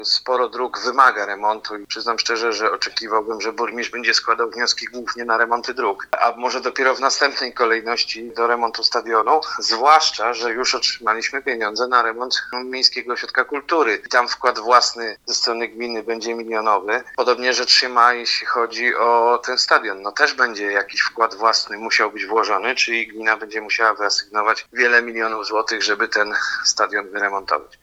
Działacze sportowi zacierają ręce, natomiast w pierwszej kolejności przydałyby się pieniądze na infrastrukturę drogową – komentuje Grzegorz Ufnarz, Radny miasta z Polic, niezależny.